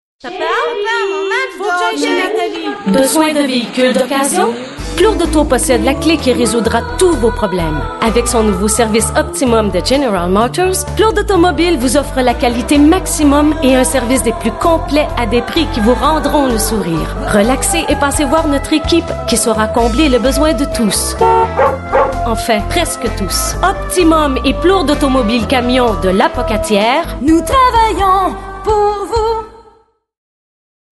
Pub
Enfants et parents du voisinage :-)